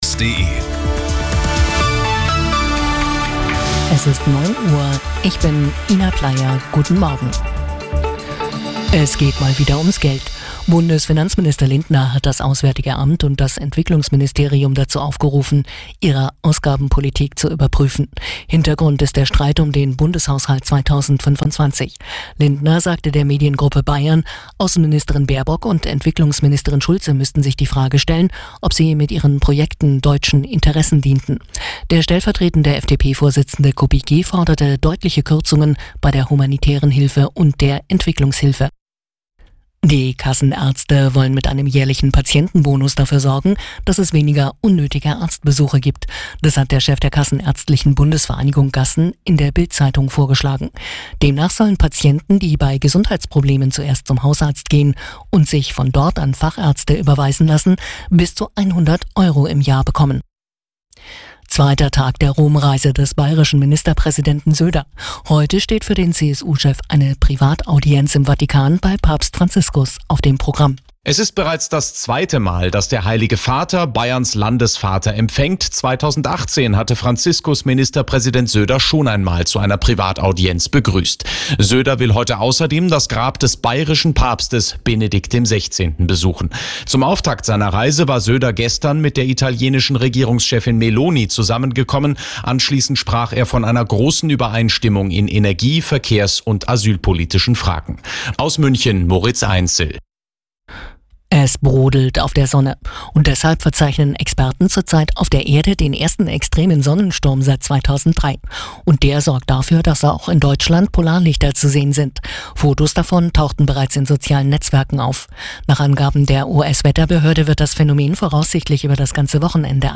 Die Arabella Nachrichten vom Samstag, 11.05.2024 um 11:06 Uhr - 11.05.2024